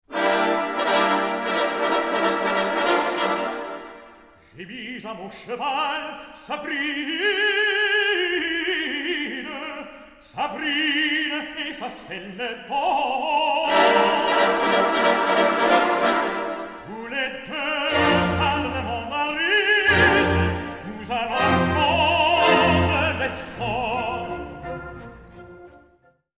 Super Audio CD
World premiere recording.